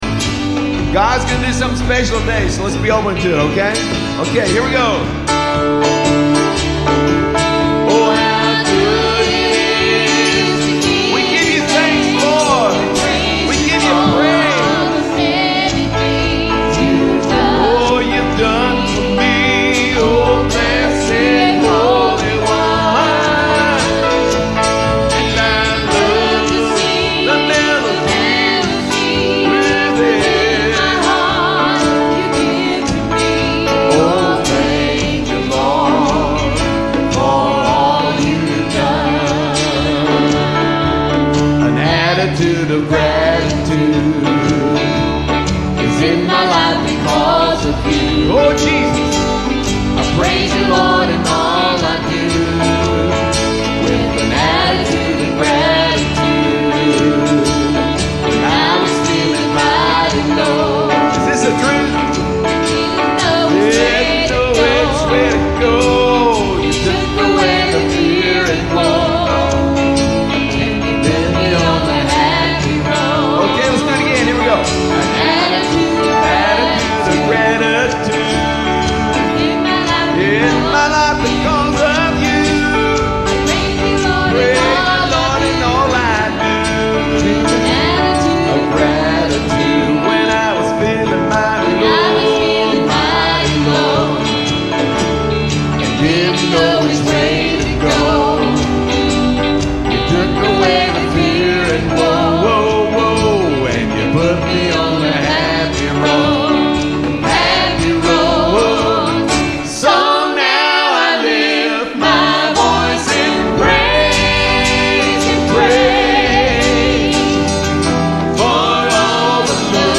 "Live Worship"     $14.95     Buy Now!